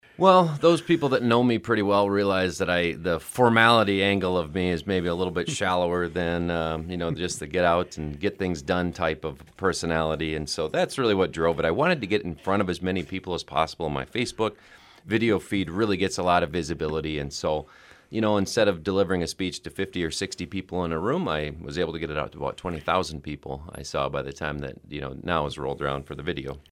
On Wednesday he appeared on the KSDN Midday Report to talk more about that.